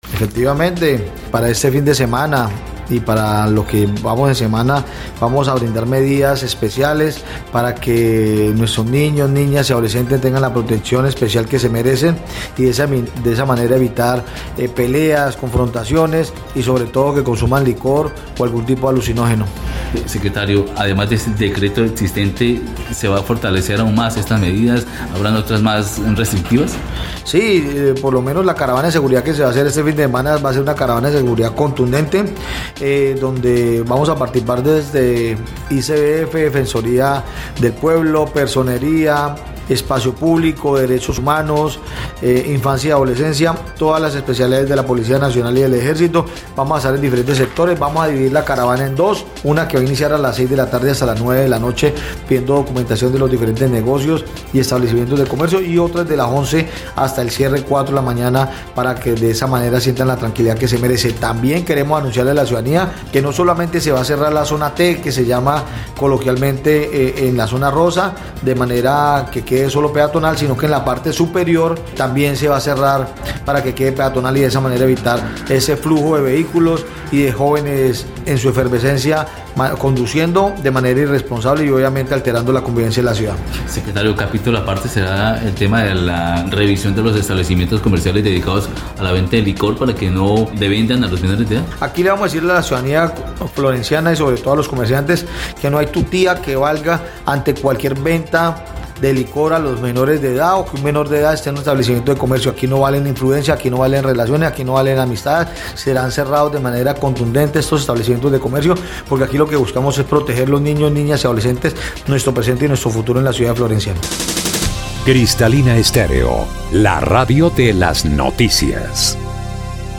Así lo dio a conocer Carlos Mora Trujillo, secretario de gobierno del municipio, quien dijo que desde la presente semana se empezarán a ejecutar una serie de operativos de control cuya finalidad será evitar que los menores de edad transiten por las calles en horarios no permitidos.